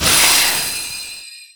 Magic_Spell16.wav